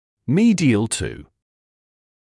[‘miːdɪəl tuː][‘миːдиэл туː] медиально по отношению к